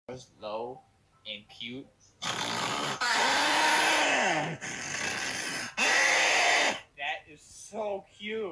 Snoring Low & Cute